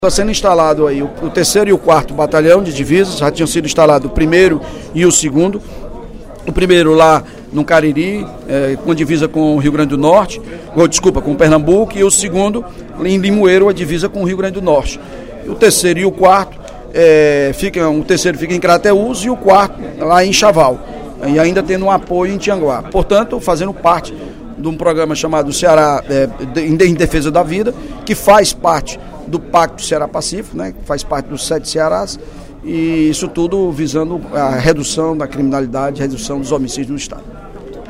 O líder do Governo, deputado Evandro Leitão (PDT), destacou, no primeiro expediente da sessão plenária desta terça-feira (01/03), o reforço na segurança entre os estados do Ceará e Piauí, com a instalação da 3ª e 4ª companhias do Batalhão de Divisas, na segunda-feira (29/02).